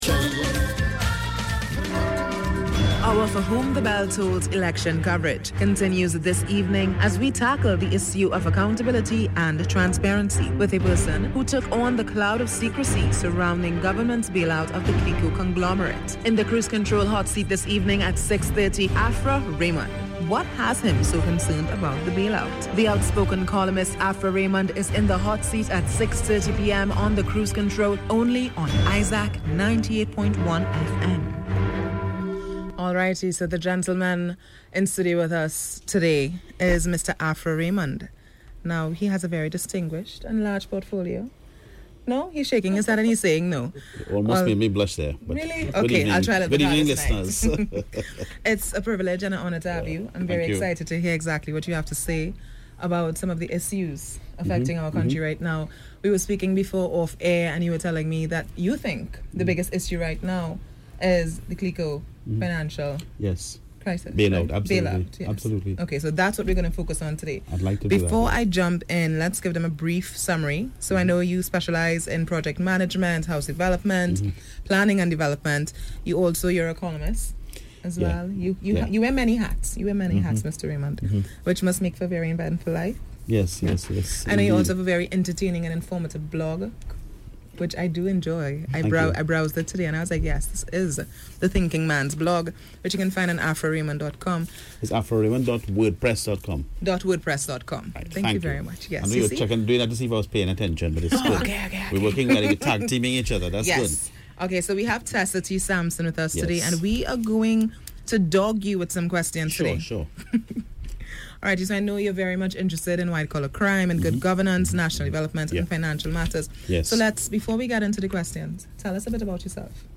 AUDIO: Cruise Control interview on Isaac 98.1 FM – 30 Jul 2015